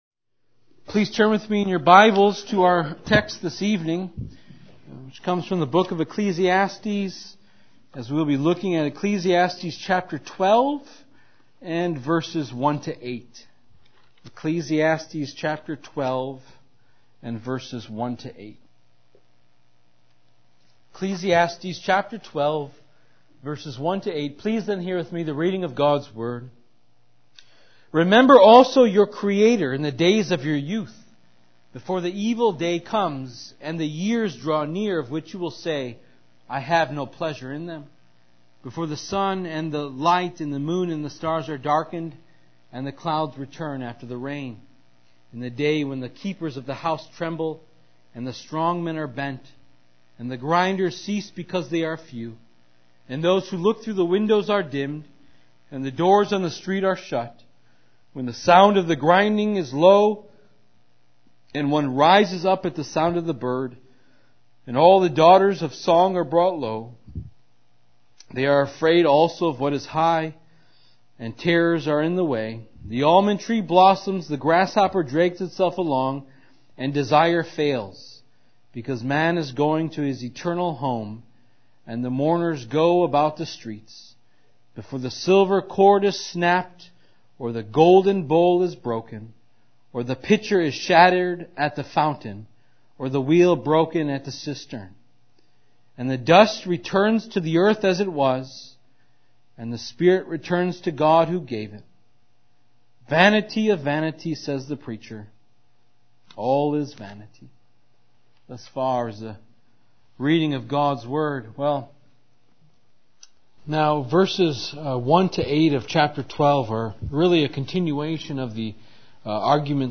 living-before-our-creator-sermon-ecclesiastes-12-1-8.mp3